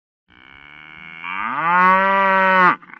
Free Animals sound effect: Cow Moo.
Cow Moo
296_cow_moo.mp3